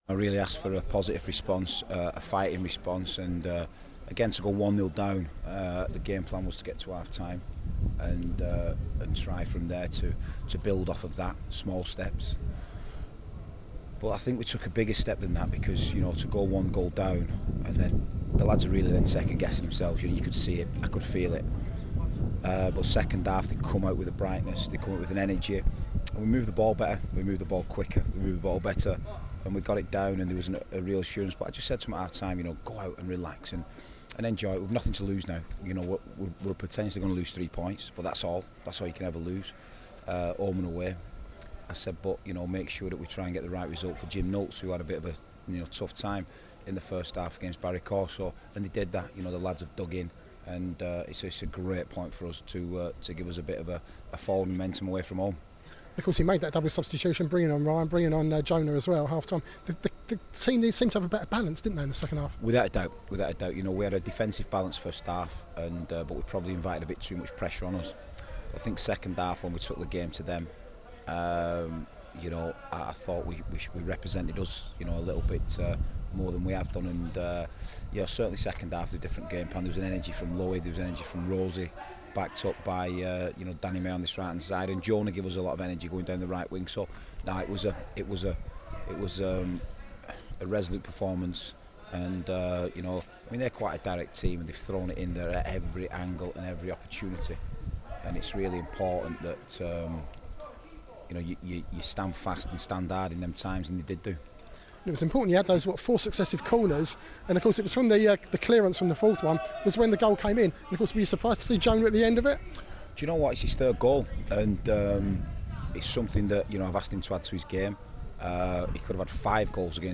speaks to BBC Radio Manchester following their 1-1 draw at Southend